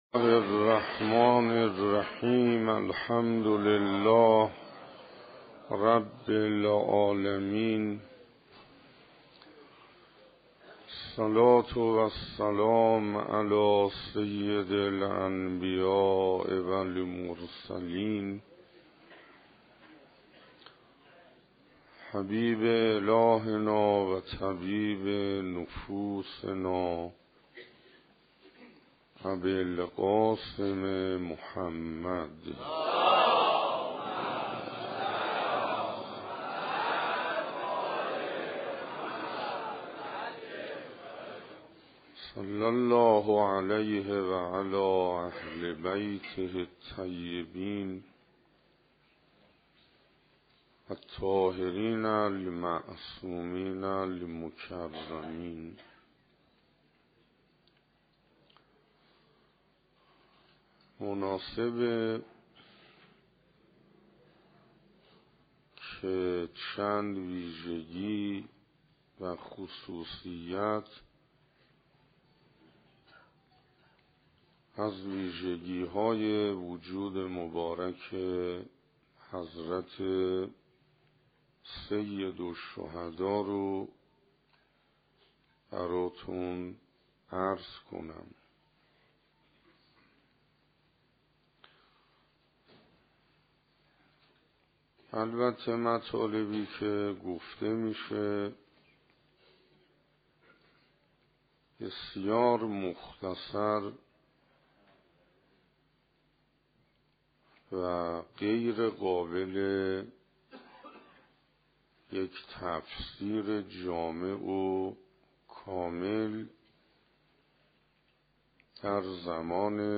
مسجد المجتبی دهه دوم صفر 95 سخنرانی دهم_معاد(مرگ از دیدگاه اسلام)مسجد المجتبی دهه دوم صفر 95 سخنرانی نهم_معاد(مرگ از دیدگاه اسلام)